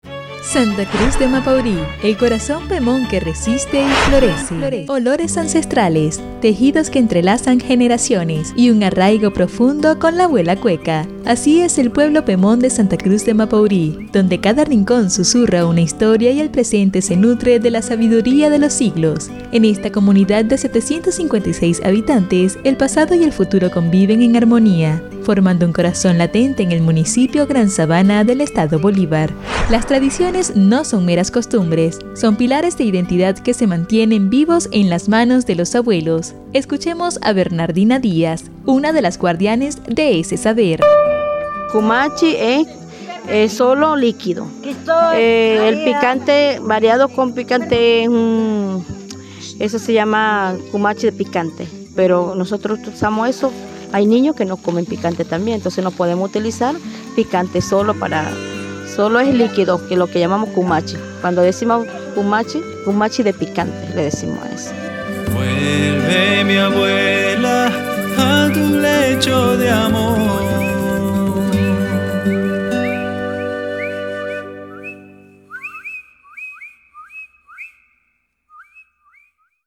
Micros radiales